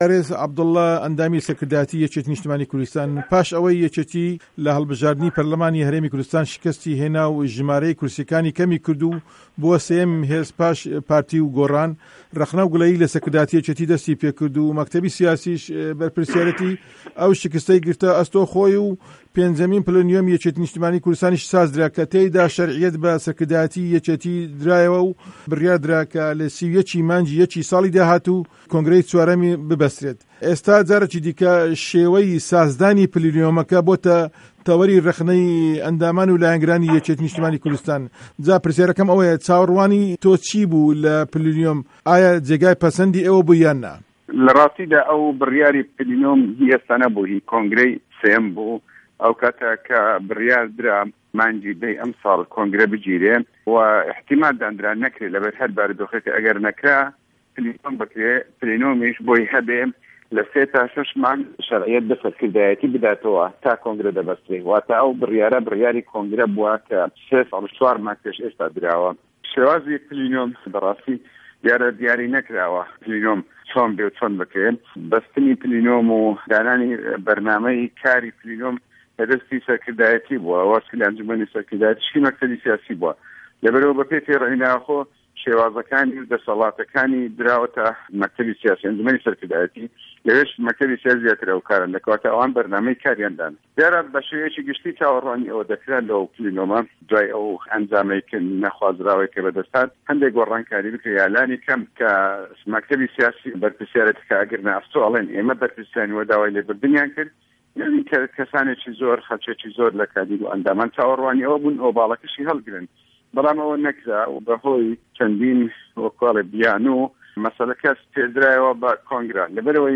وتووێژی ئارێز عه‌بدوڵڵا